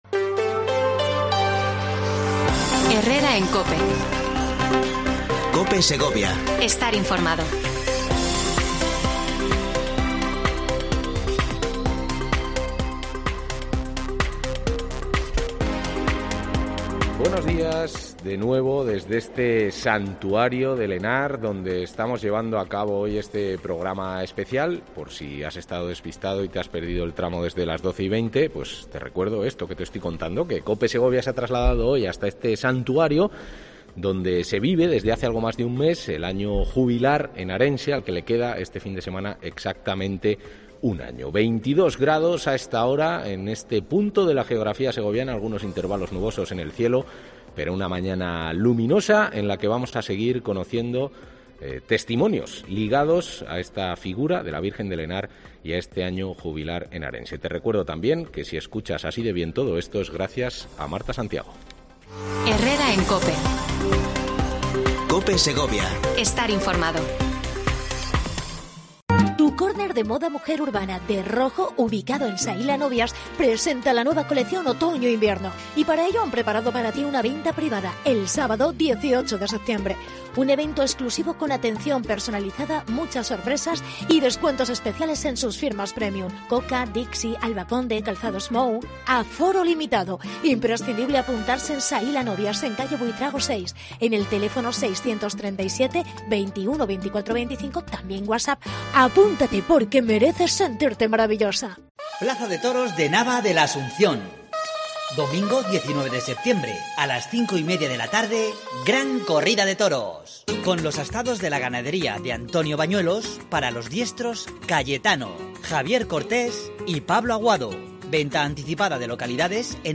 PROGRAMA ESPECIAL AÑO JUBILAR HENARENSE DESDE EL SANTUARIO VIRGEN DE EL HENAR II